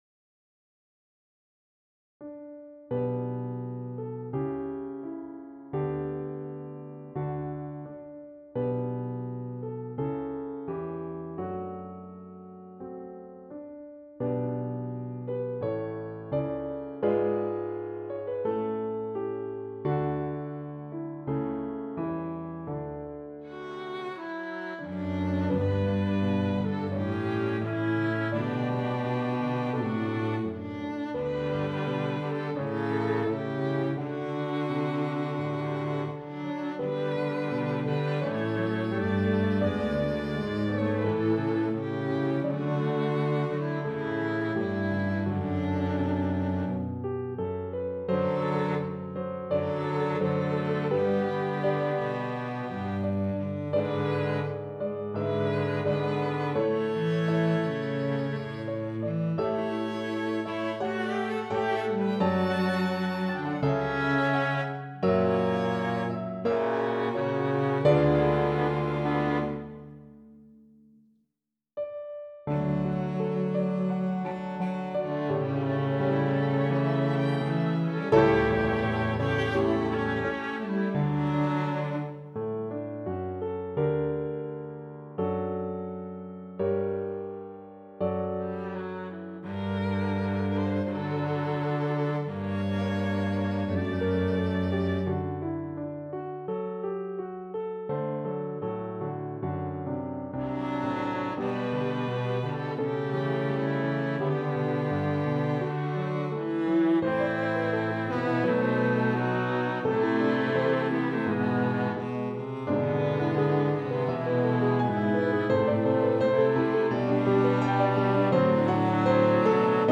Sound great. Needs a few custom tweaks for playback, but really pleased.
New VST violin and viola. Much better, and better balance. 2. Improved piano passage work rendering/claified muddy scoring. 3. fixed piano transition into A section coda. 4. made melodic line improvements. PQ II Andante v.FINa_Convert to_MP3.mp3 Edited April 24